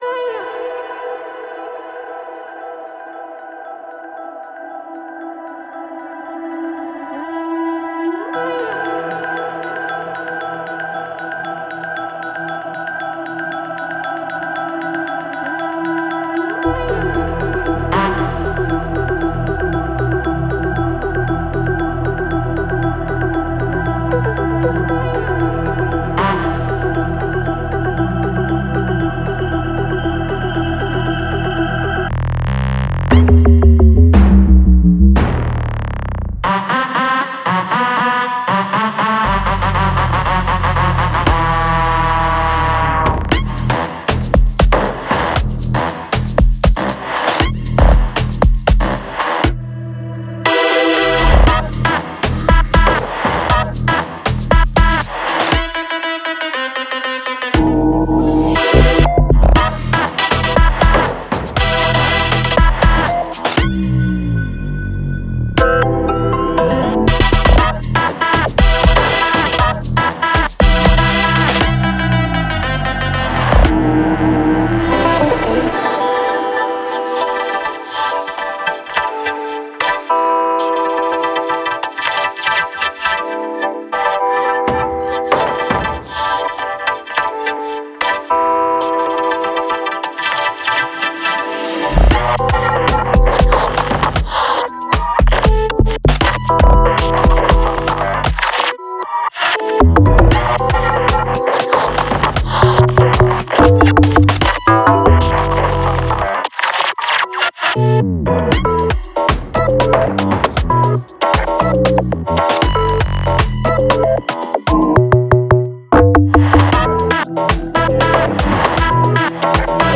Genre:Amapiano
デモサウンドはコチラ↓